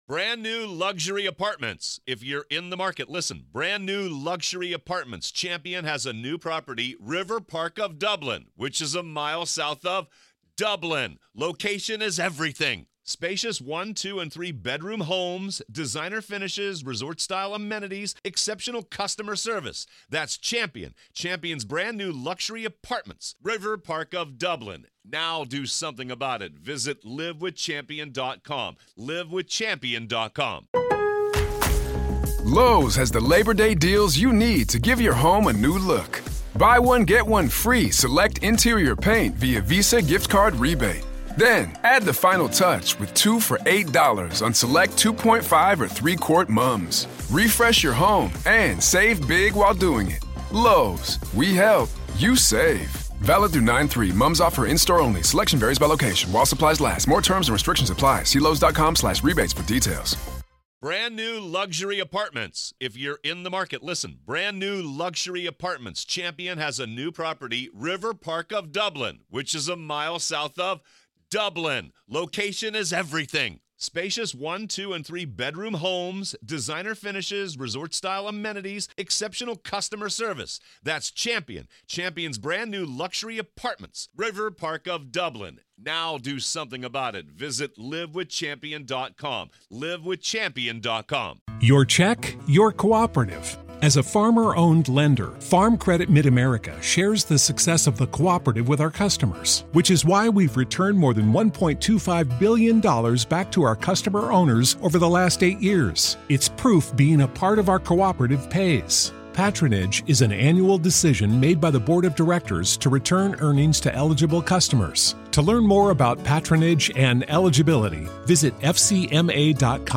Experience the raw courtroom drama firsthand as we delve into the "Rust" movie shooting trial with unfiltered audio and expert analysis. Witness the opening statements in the case against weapons supervisor Hannah Gutierrez-Reed, the first trial related to the tragic death of cinematographer Halyna Hutchins on the set of the Western film. Go beyond the headlines: Hear the emotional pleas and heated arguments directly from the courtroom.